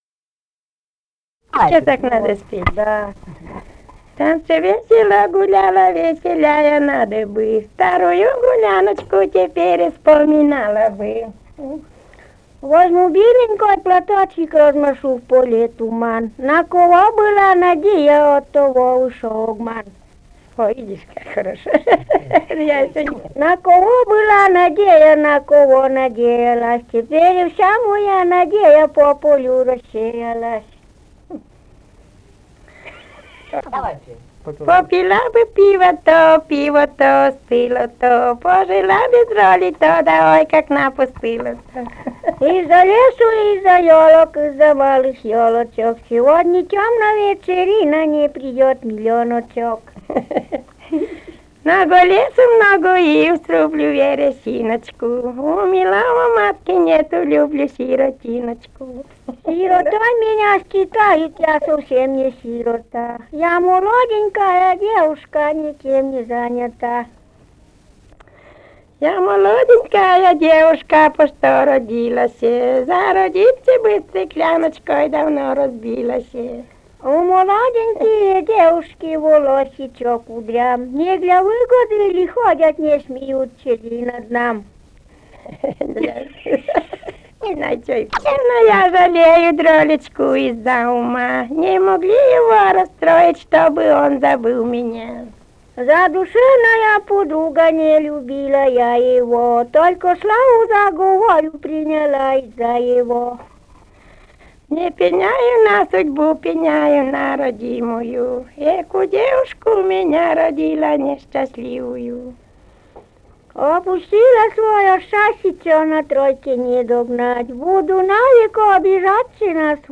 «В танце весело гуляла» (частушки).